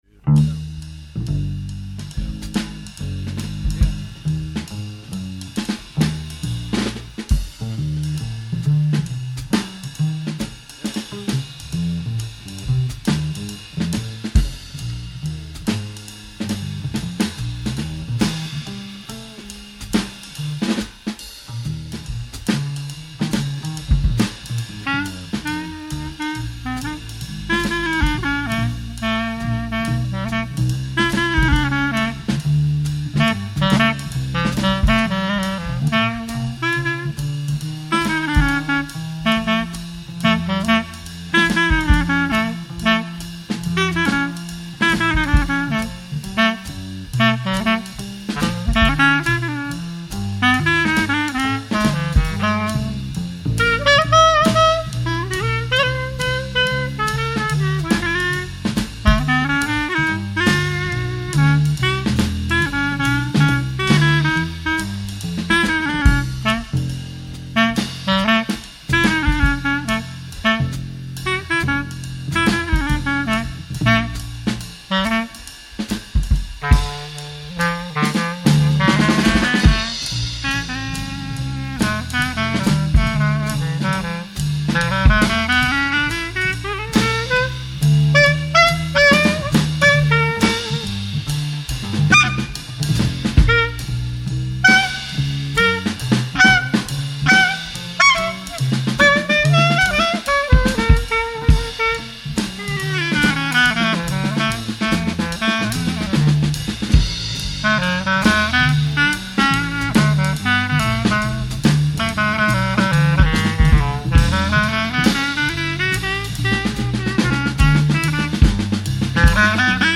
Recorded in concert at Jazz Club Fasching,
Stockholm, Sweden, on June 4, 1988.